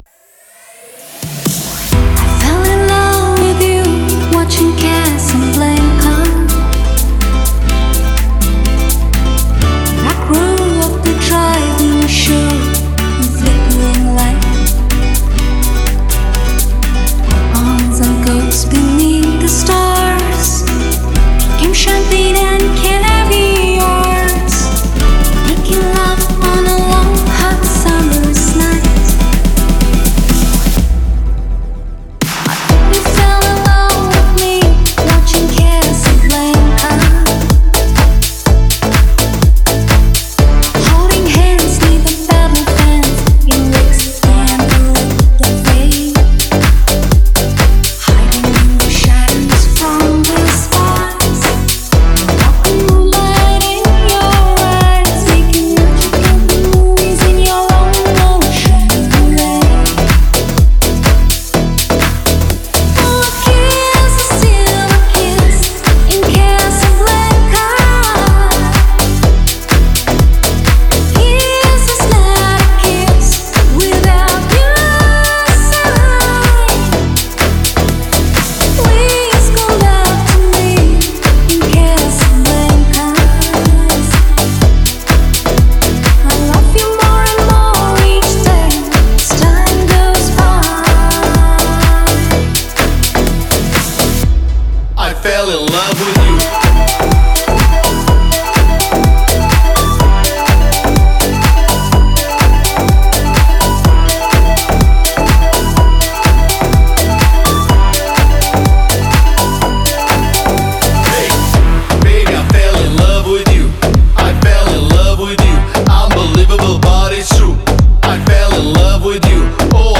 это зажигательный трек в жанре EDM
Смелые мелодии и запоминающийся вокал